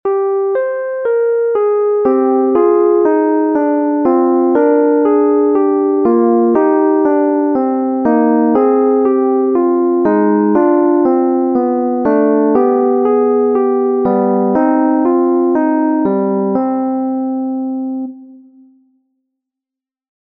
Great words, and the nicely harmonised response follows an easy, descending path of similar phrases.
For what it’s worth here are the notes, admittedly in deplorably mechanical style, but you can at least follow together with those good lines quoted above :